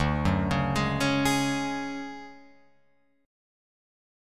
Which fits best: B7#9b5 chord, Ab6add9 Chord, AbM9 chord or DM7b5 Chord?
DM7b5 Chord